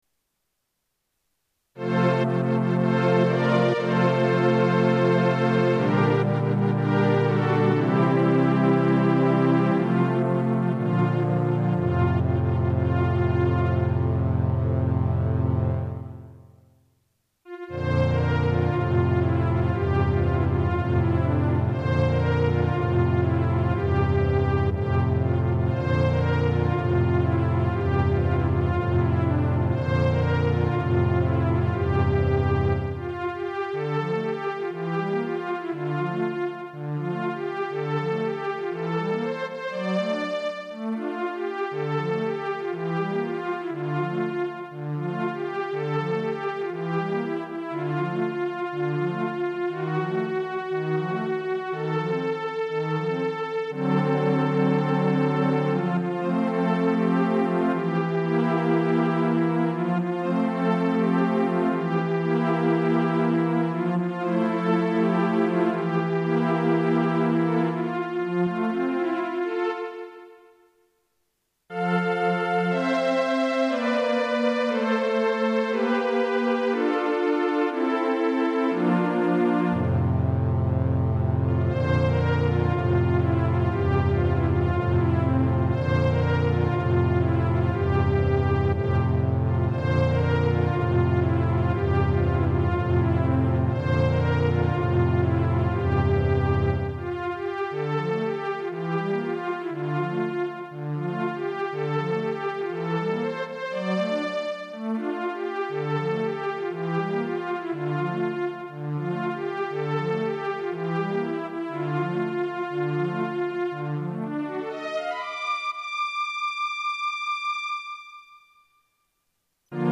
相変わらずクラシカルな曲です。
運命の悪戯とか浮き沈みする人生的なものを表現したかった。のでこんな曲調の変化が何箇所も入ってるんですが、それっぽく聞こえるものかどうか。